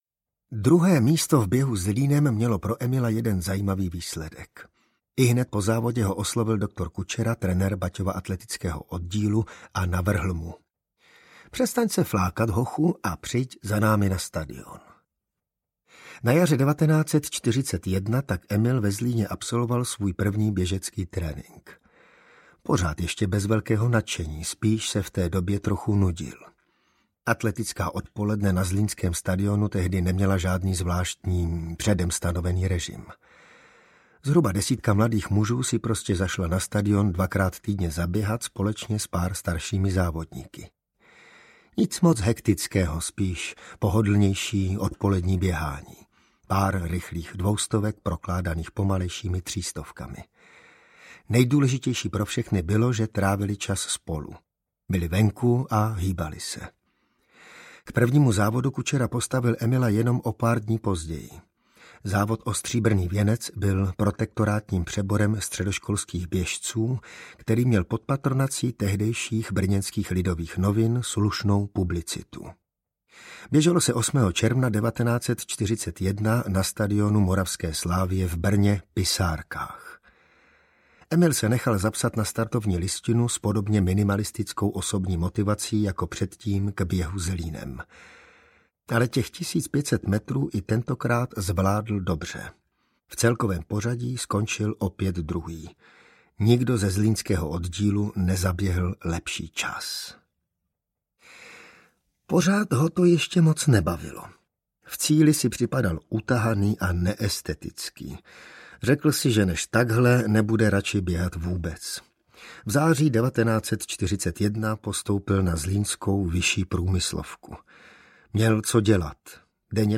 Emil Běžec audiokniha
Emil Běžec - audiokniha obsahuje úryvky z prvního uceleného životopisu sportovní legendy doplněné dokumentárními nahrávkami. Čte Ivan Trojan.
emil-bezec-audiokniha